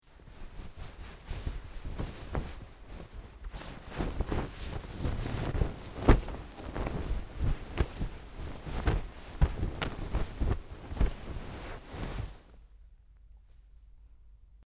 MovementOnBedSheet PE382102
Movement On Bed With Sheet Zuzz, X2